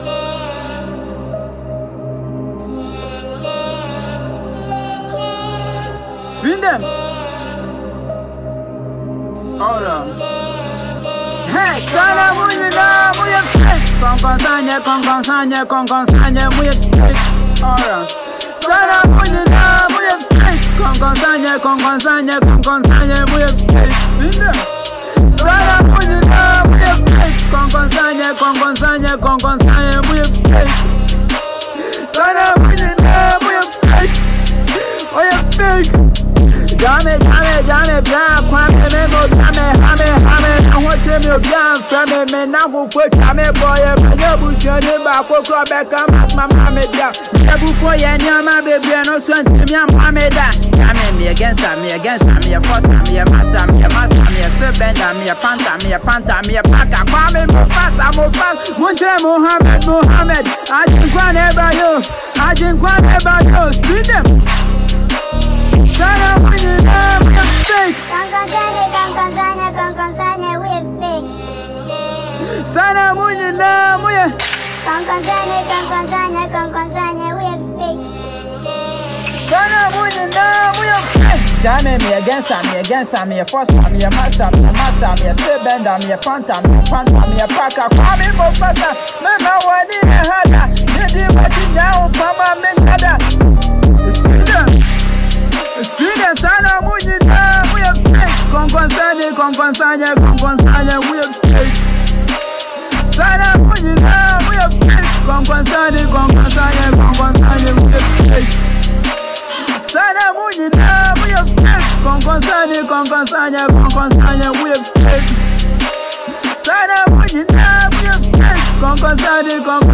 the popular Ghanaian rapper
The song features catchy beats and powerful lyrics.